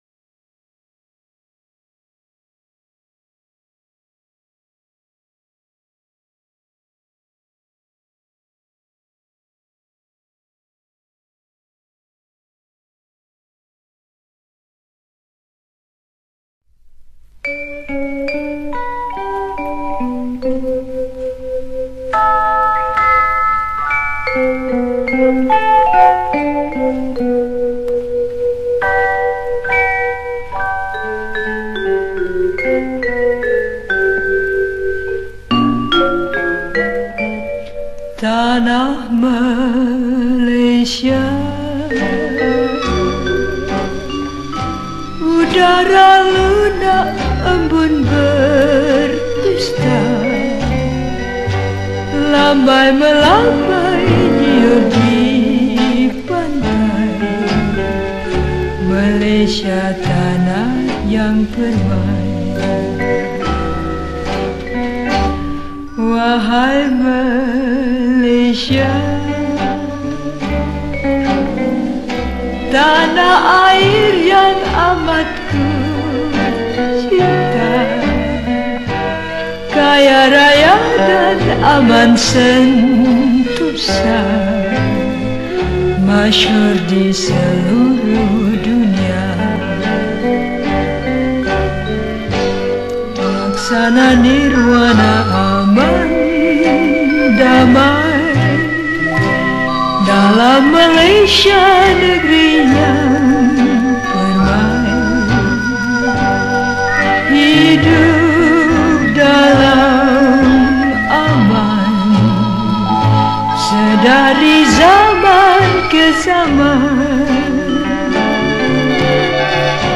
Malaysian Patriotic Retro Song
Skor Angklung